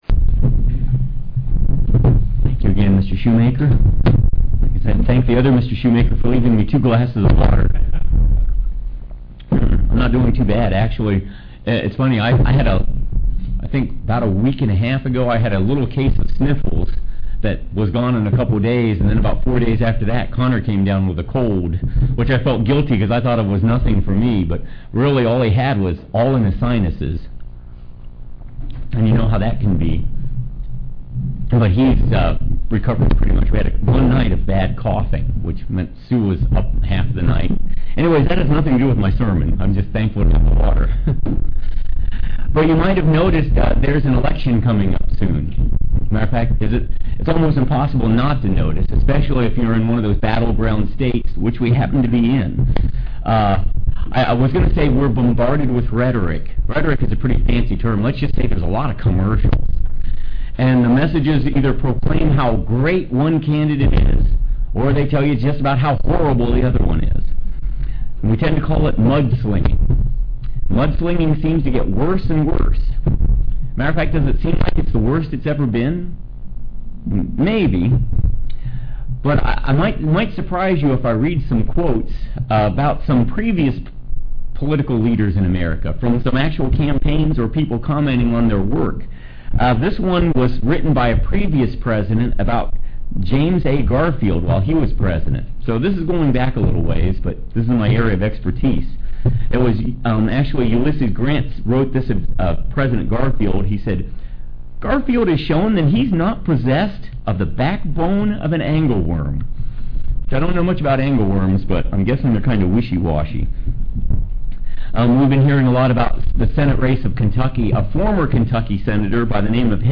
UCG Sermon Transcript